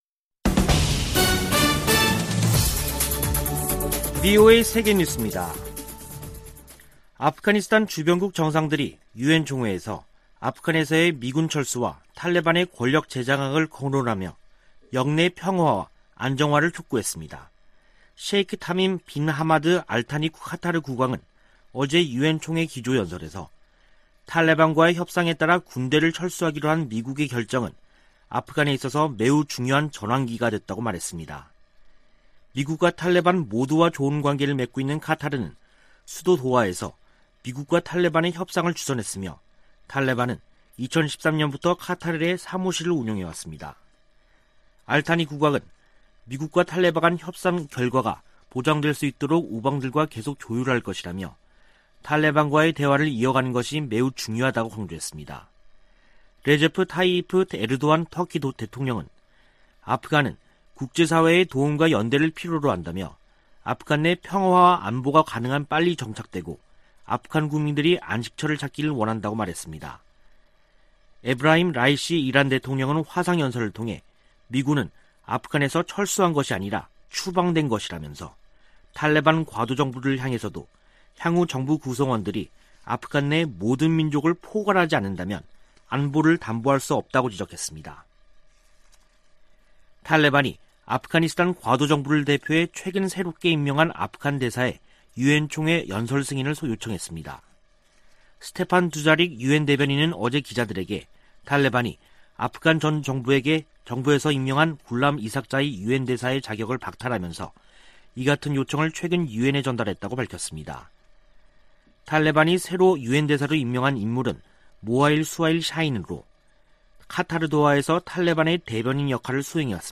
VOA 한국어 간판 뉴스 프로그램 '뉴스 투데이', 2021년 9월 22일 2부 방송입니다. 조 바이든 미국 대통령이 한반도 완전 비핵화를 위해 지속적 외교와 구체적 진전을 추구한다고 밝혔습니다. 문재인 한국 대통령은 종전선언을 제안했습니다. 미 연방수사국(FBI)이 북한의 사이버 역량 증대를 지적했습니다.